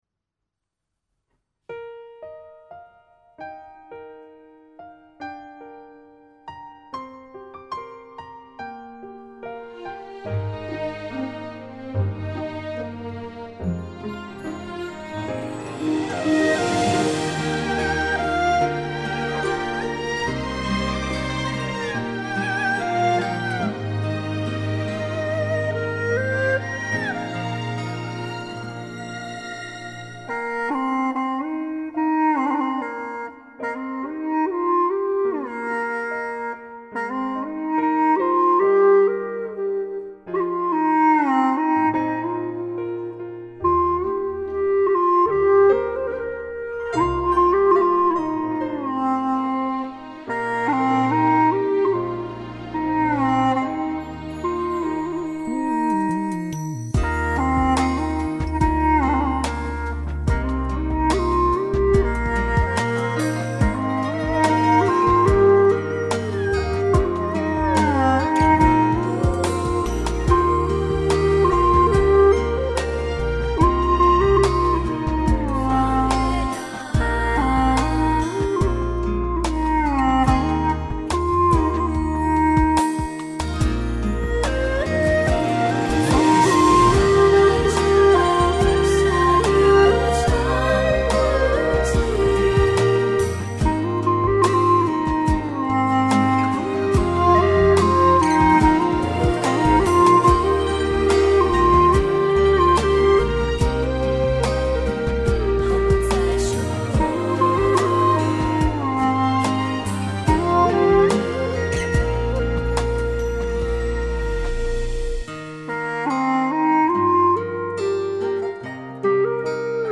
调式 : 降E 曲类 : 古风